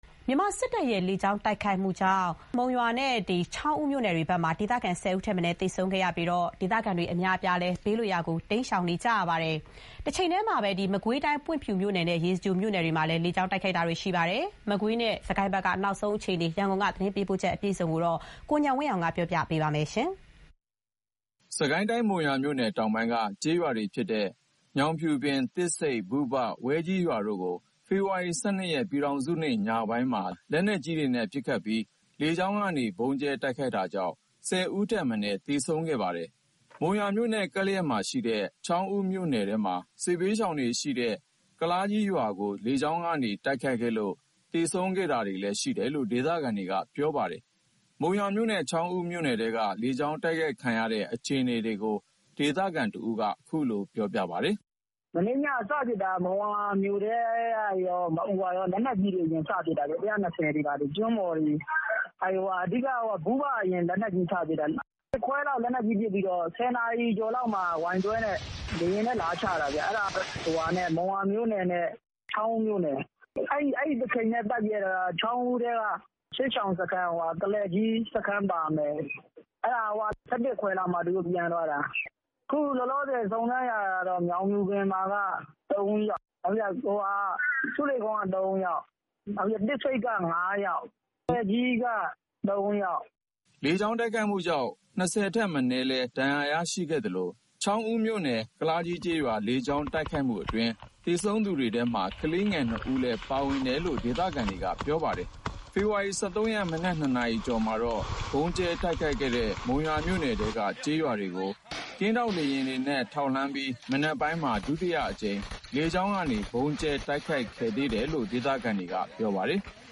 စစ်တပ်ရဲ့လေကြောင်းတိုက်ခိုက်မှုကြောင့် မုံရွာမြို့နဲ့ ချောင်းဦး မြို့နယ်ထဲမှာ ဒေသခံ ၁၀ ဦးထက်မနည်း သေဆုံးခဲ့ပြီး ဒေသခံတွေ ဘေးလွတ်ရာ တိမ်းရှောင်နေရပါတယ်။ မကွေးတိုင်း ပွင့်ဖြူမြို့နယ်နဲ့ ရေစကြိုမြို့နယ်တို့မှာလည်း လေကြောင်းတိုက်ခိုက်တာတွေ ရှိခဲ့ပါတယ်။ စစ်တပ်ရဲ့ လေကြောင်းတိုက်ခိုက်မှု အခြေအနေ ရန်ကုန်ကပေးပို့လာတဲ့ သတင်း ဖြစ်ပါတယ်။
မုံရွာမြို့နဲ့ ချောင်းဦး မြို့နယ်ထဲက လေကြောင်းတိုက်ခိုက်ခံရတဲ့ အခြေအနေတွေကို ဒေသခံတဦးက အခုလို ပြောပါတယ်။